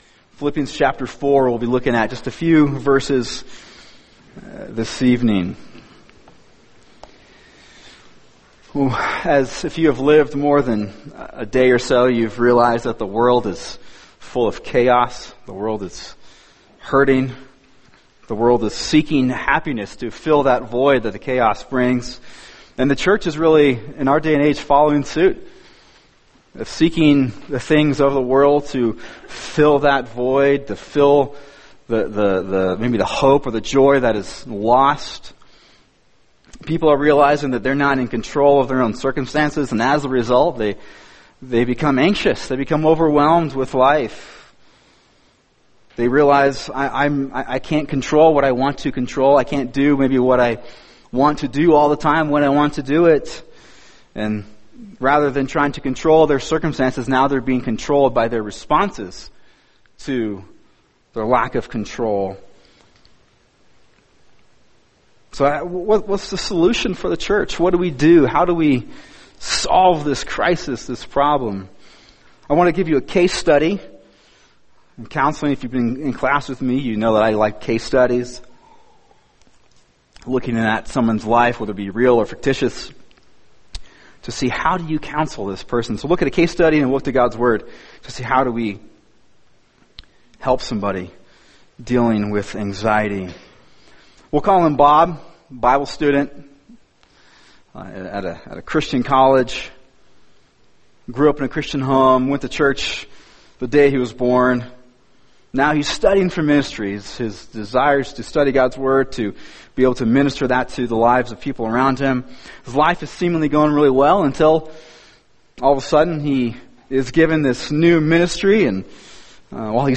[sermon] Philippians 4:6-9 – God’s Answer For Anxiety | Cornerstone Church - Jackson Hole